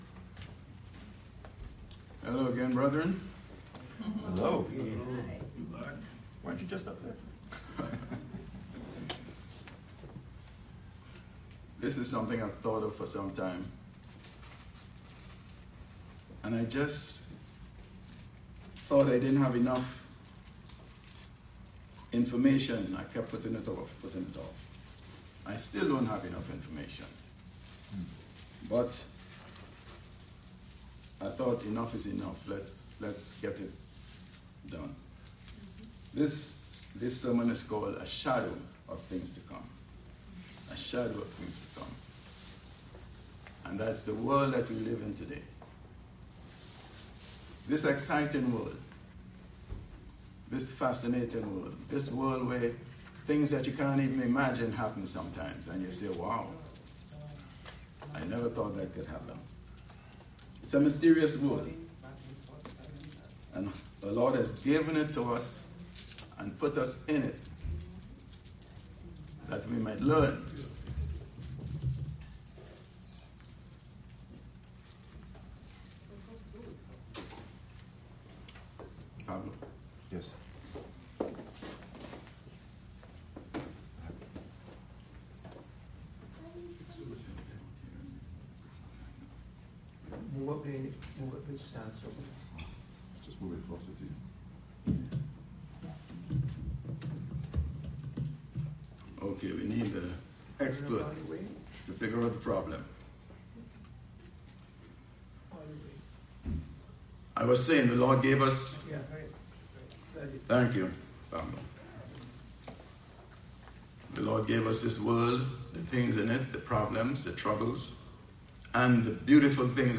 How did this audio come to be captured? Given in New Jersey - North New York City, NY